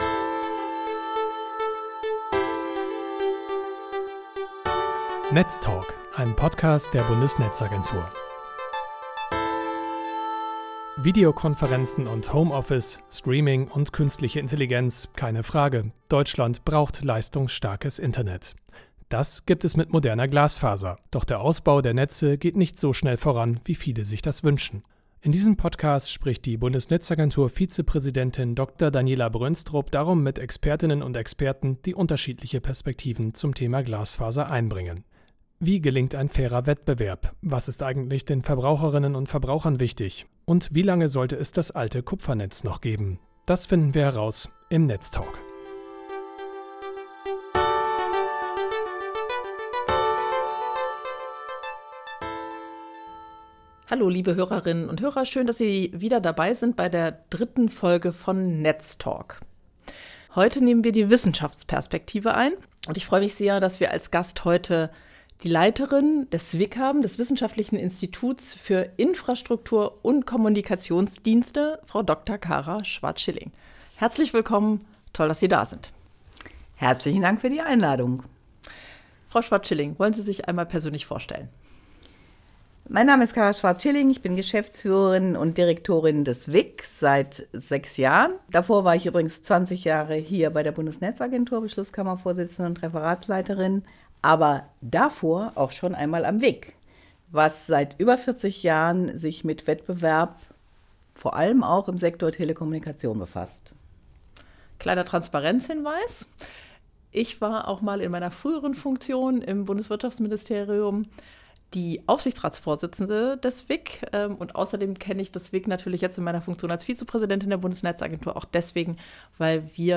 Im Gespräch mit BNetzA-Vizepräsidentin Dr. Daniela Brönstrup finden Sie die Antworten auf diese und viele andere Fragen.